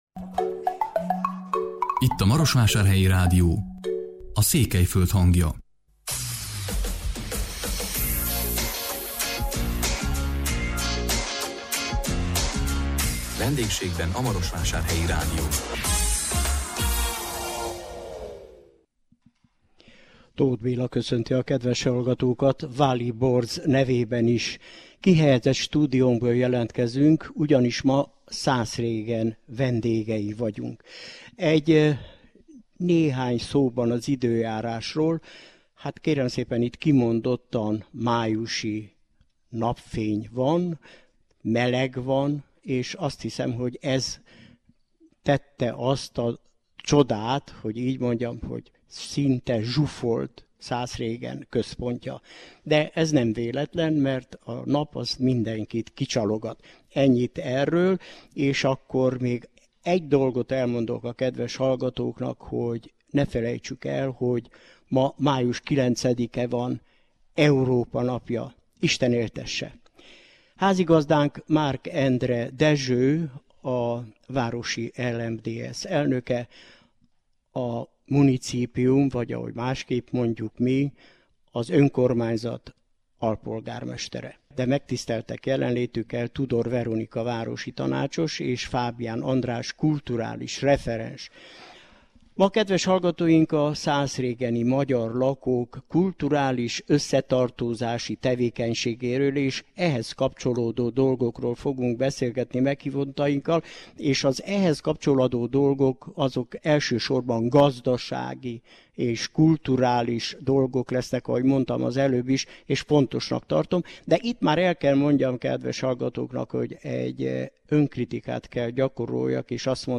A 2019 május 9-én jelentkező műsorunkban a Maros megyei Szászrégen vendégei voltunk. Meghívottainkkal a kulturális életről, az összetartozás erősítéséről és az ehhez kötődő dolgokról beszélgettünk.